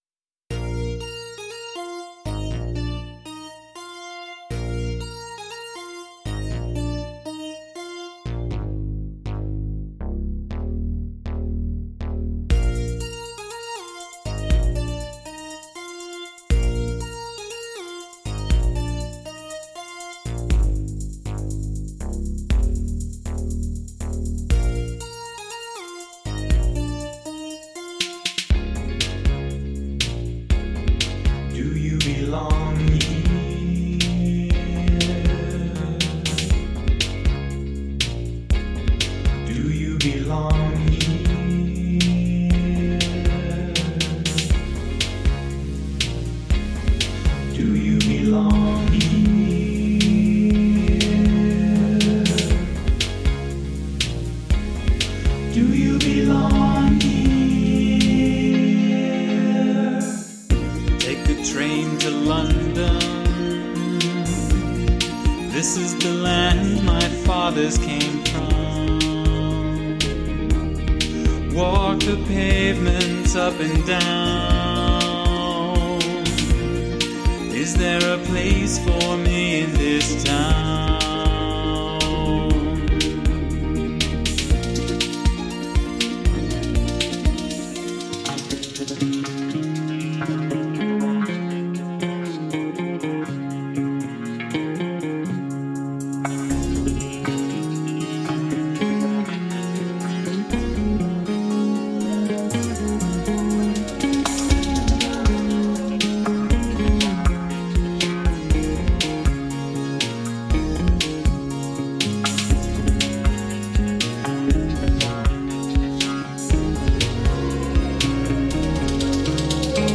vocoders, synthesizers, drums
vocals, guitars, bass, synthesizers